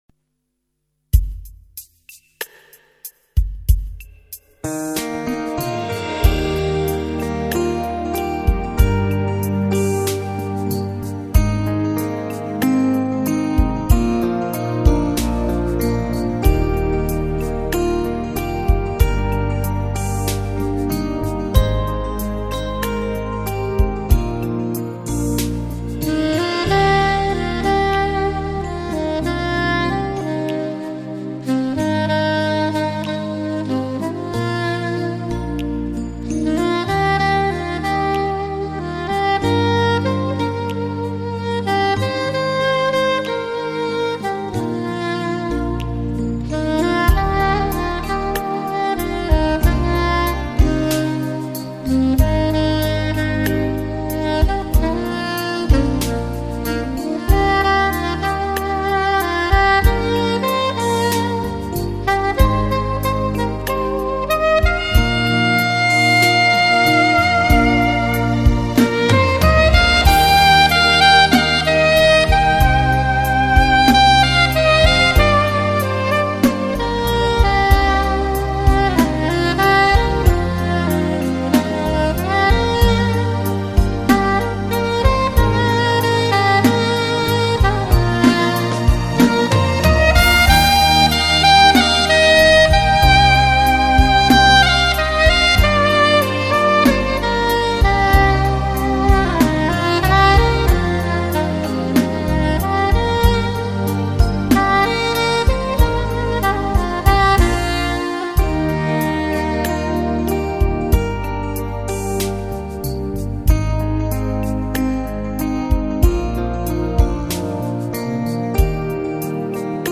Trình Bày : Hòa Tấu